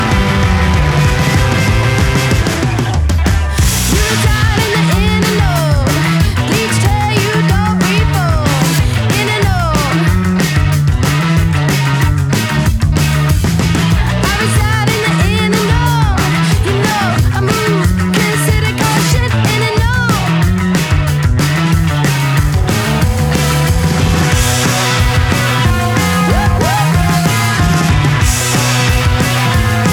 # New Wave